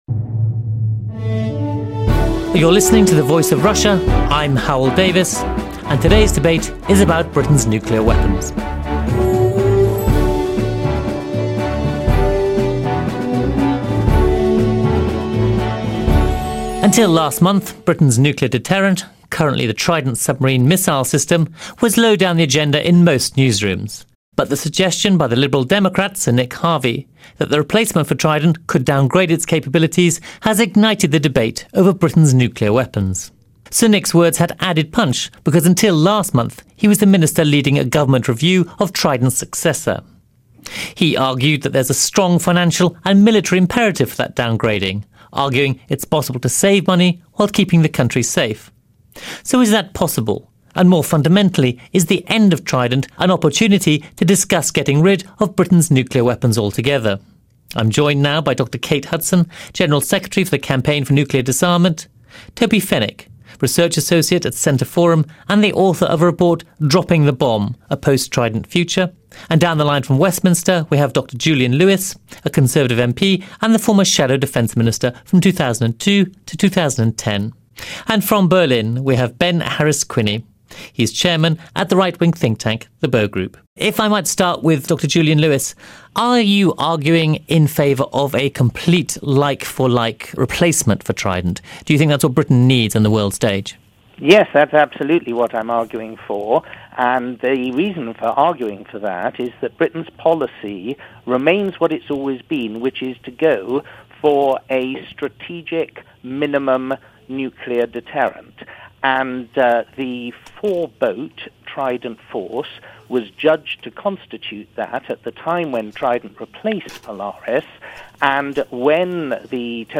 trident-discussion.mp3